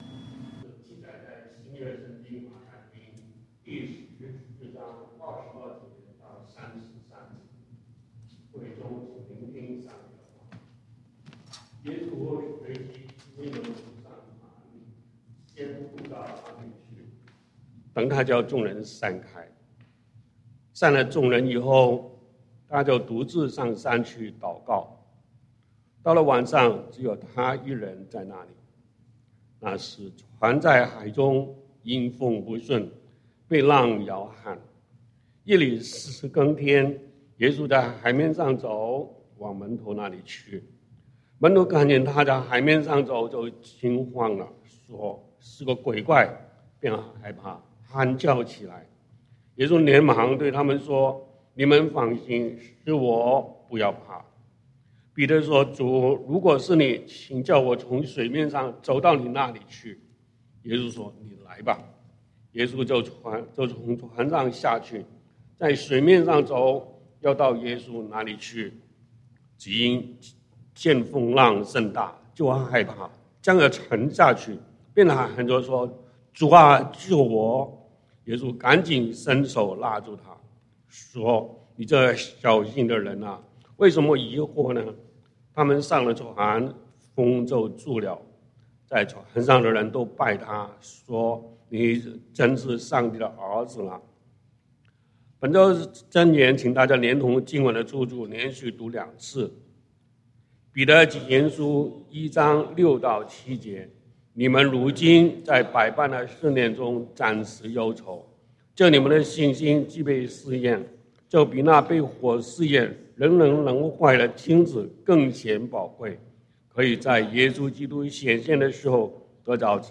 講道經文：《馬太福音》Matthew 14:22-33 本週箴言。